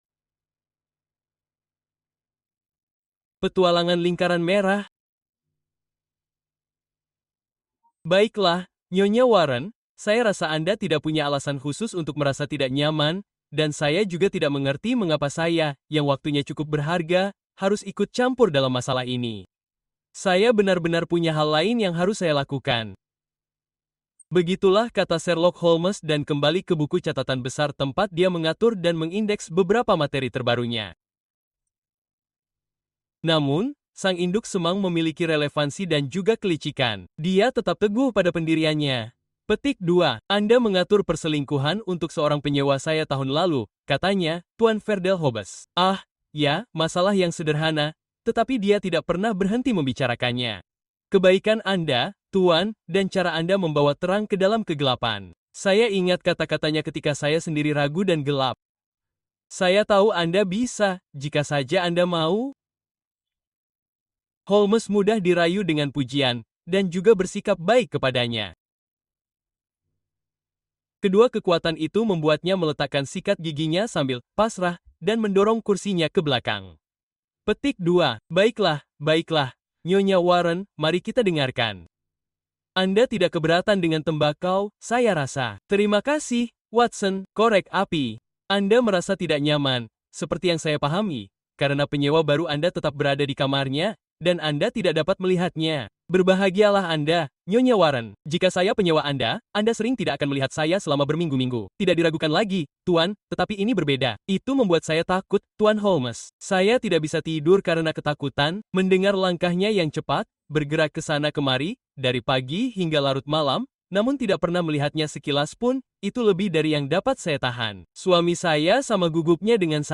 Black Peter: Sherlock Holmes Confronts Evil (Audiobook)